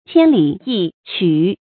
千里一曲 注音： ㄑㄧㄢ ㄌㄧˇ ㄧ ㄑㄩˇ 讀音讀法： 意思解釋： 比喻舉止隨便，不拘小節。